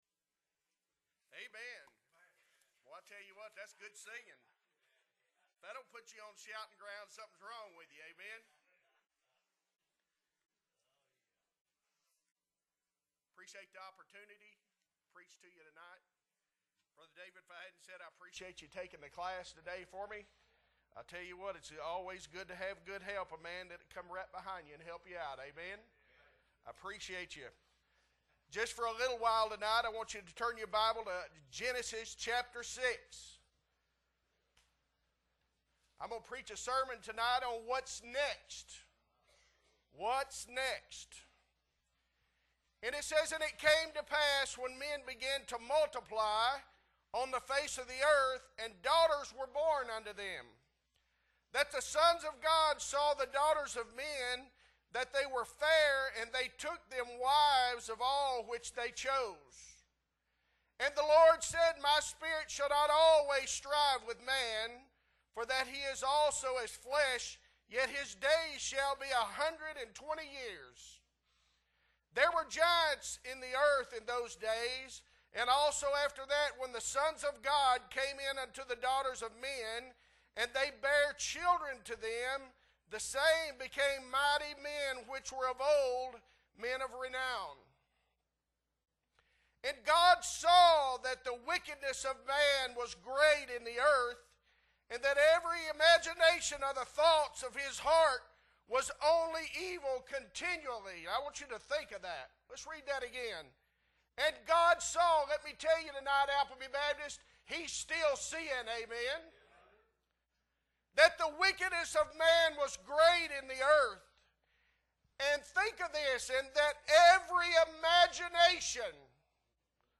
2.5.23 Evening Service - Appleby Baptist Church